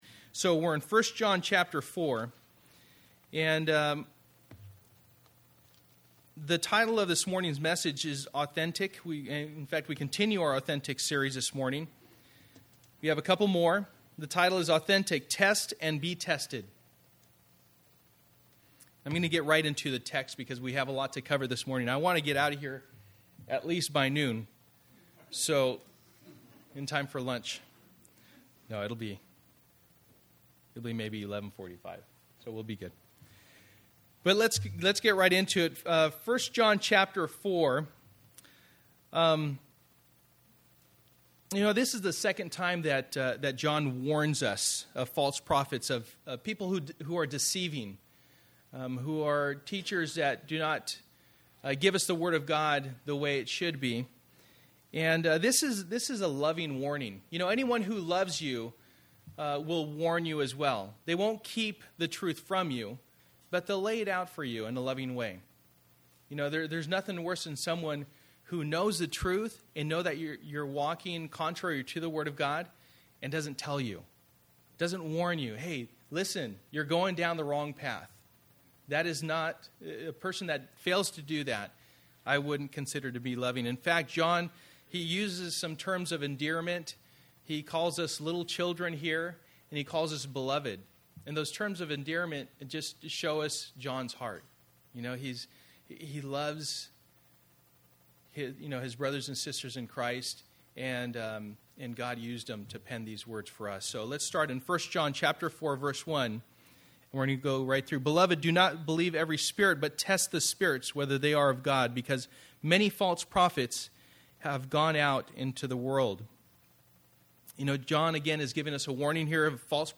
Authentic Passage: 1 John 4:1-5:5 Service: Sunday Morning %todo_render% « Band of Brothers